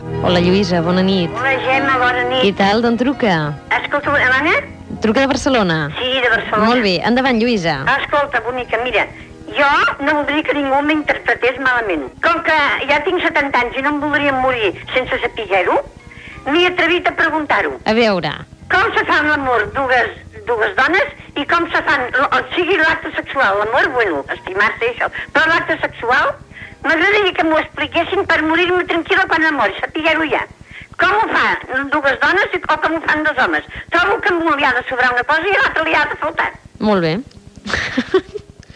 Una oïdora truca per preguntar com és l'acte sexual dels homosexuals.